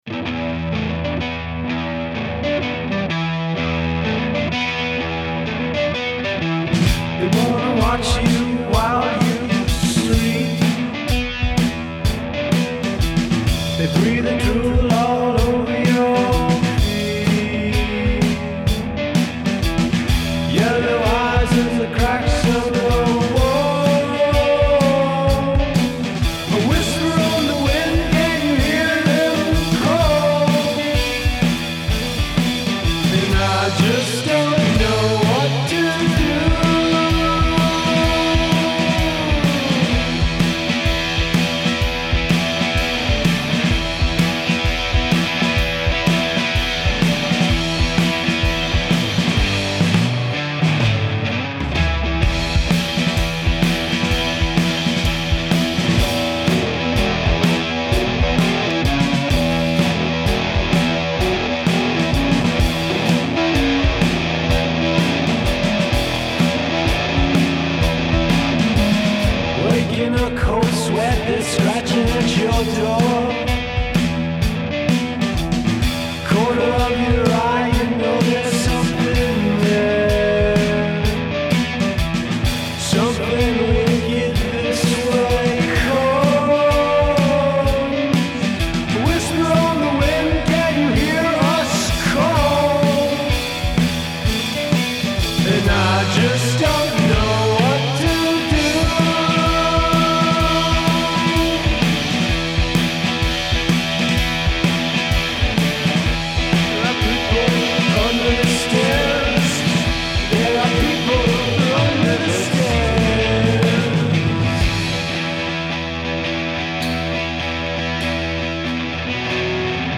Feedback on blues rock song, "People Under the Stairs"